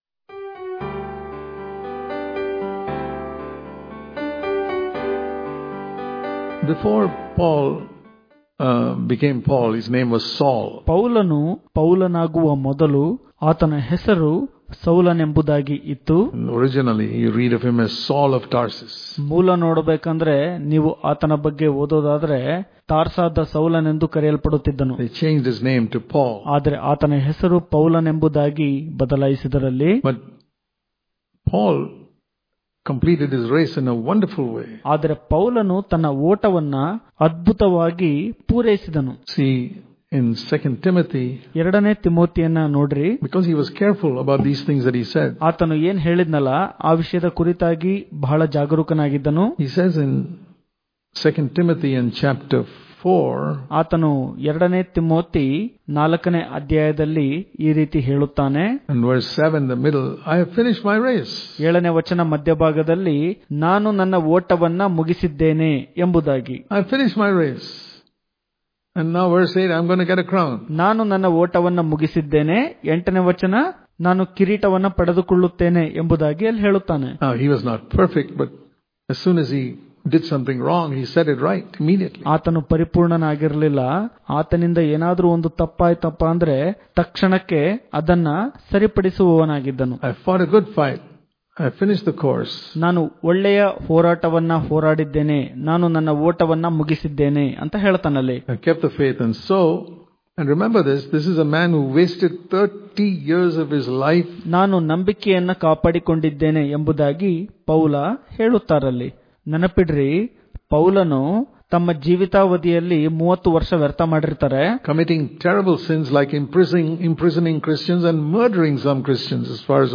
August 31 | Kannada Daily Devotion | Don't Be A Dropout In The Christian Life Daily Devotions